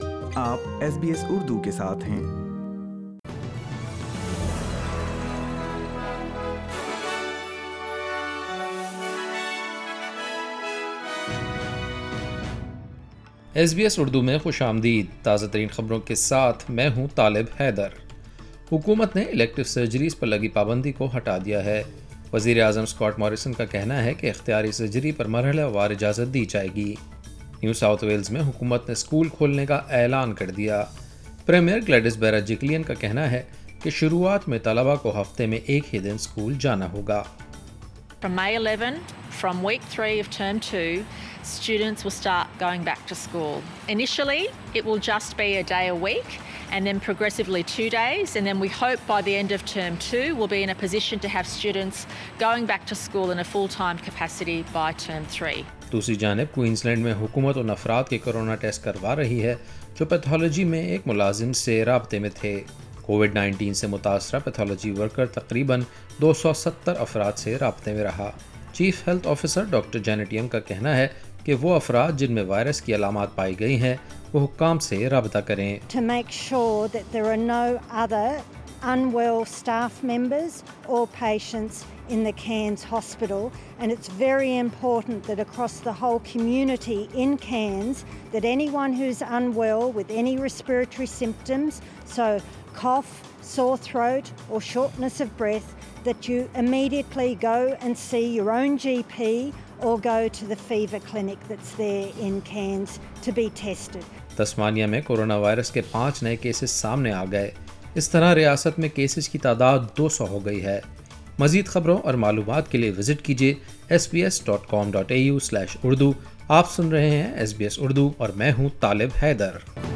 Listen to the latest Australian news on SBS Urdu.